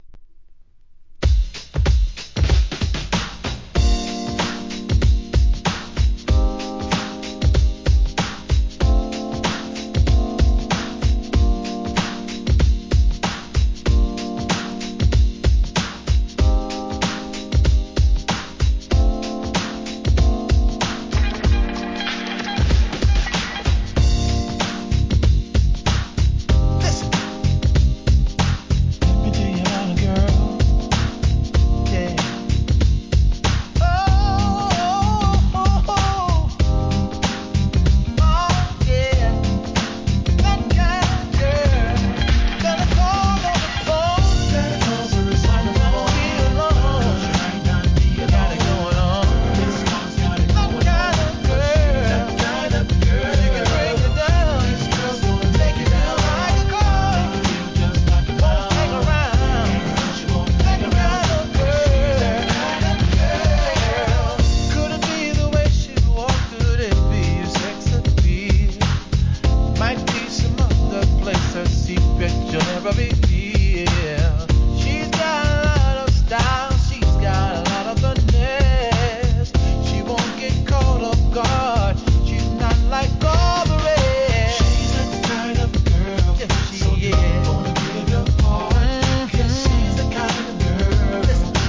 HIP HOP/R&B
路線のキャッチーなBEATで好R&B!!